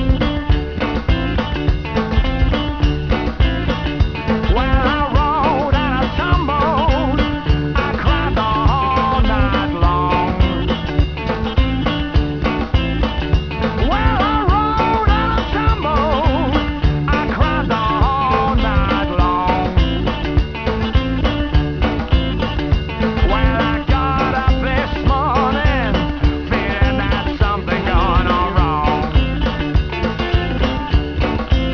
All samples are 8bit 11KHz mono recordings
Rhythm & Blues experience you'll never forget